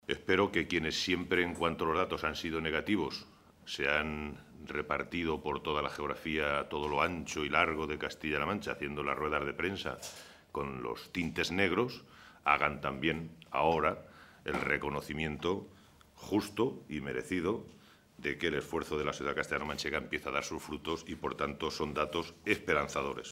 Santiago Moreno, portavoz del Grupo Parlamentario Socialista
Cortes de audio de la rueda de prensa